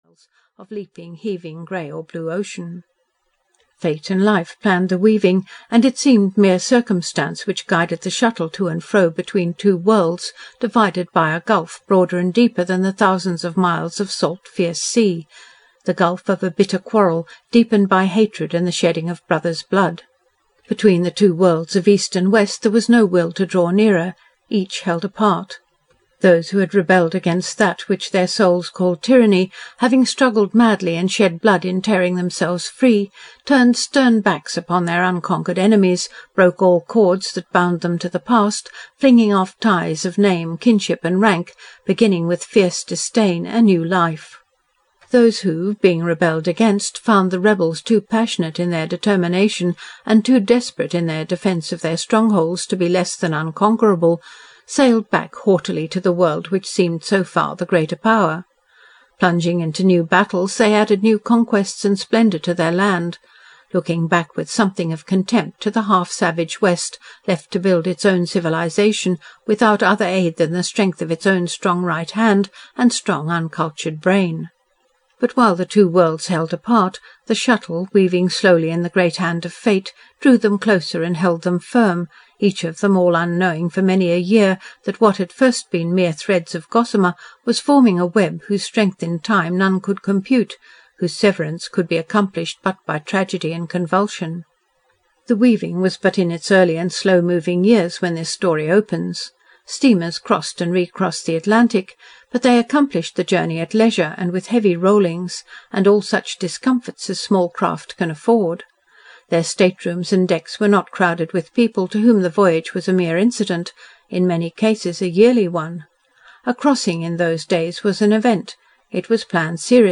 The Shuttle (EN) audiokniha
Ukázka z knihy